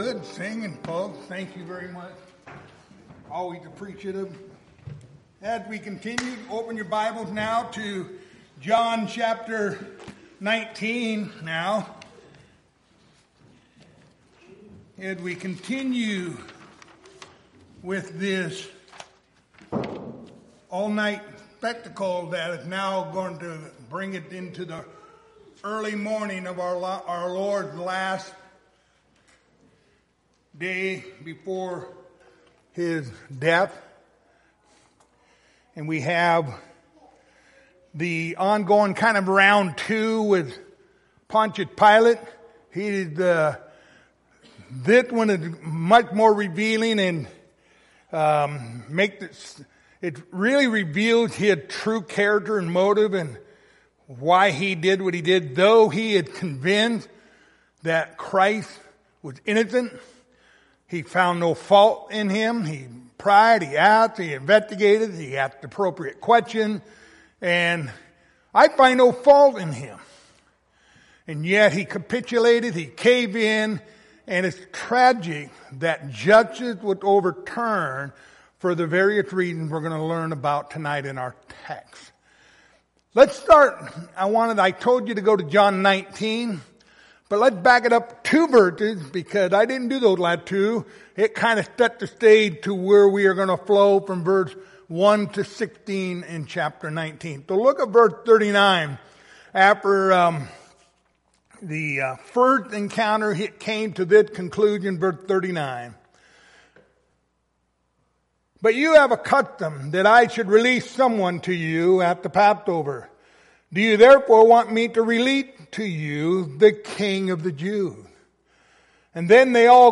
Passage: John 19:1-16 Service Type: Wednesday Evening